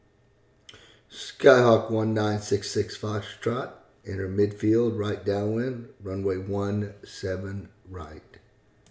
Aviation Radio Calls
13b_TowerEnterMidFieldRightDownwindRunwayOneSevenRight.mp3